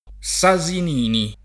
[ S a @ in & ni ]